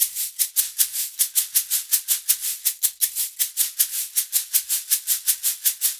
Index of /90_sSampleCDs/USB Soundscan vol.36 - Percussion Loops [AKAI] 1CD/Partition A/05-80SHAKERS
80 SHAK 04.wav